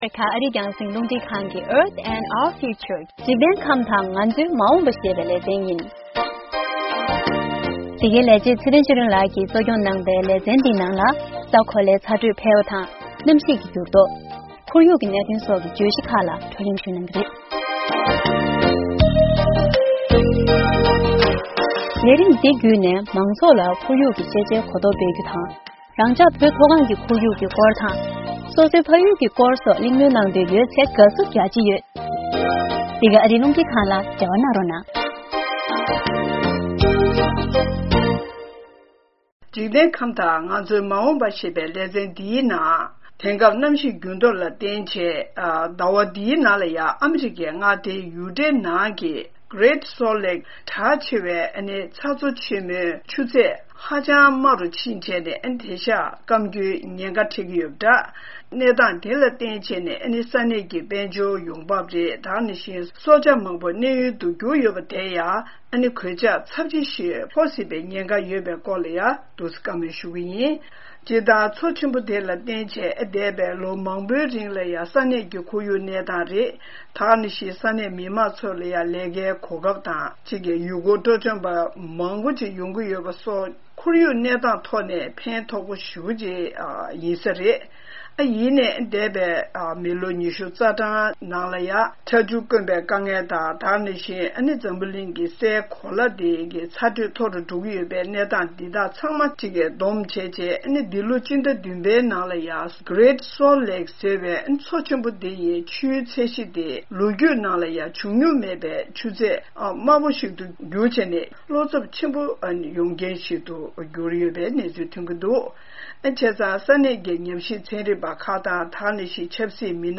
སྙན་སྒྲོན་ཞུས་ཡོད།